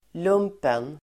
Uttal: [²l'um:pen]